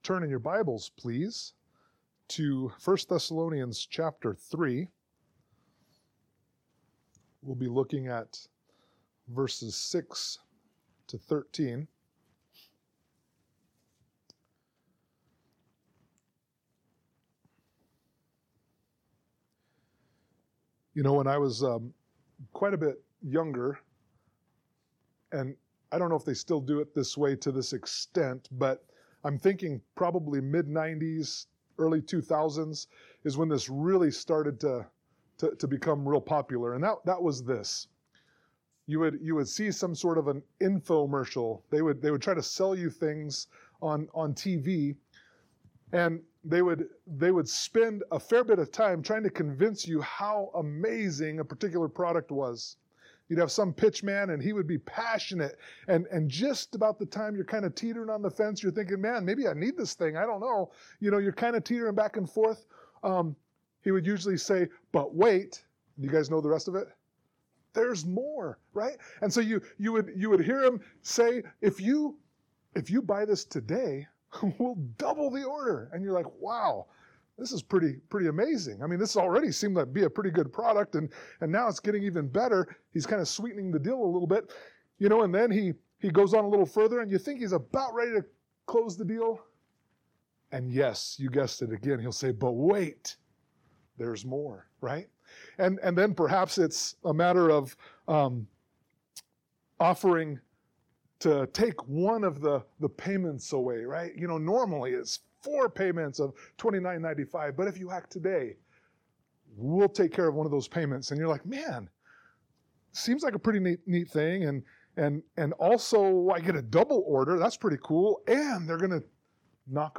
sermon-3_23_24.mp3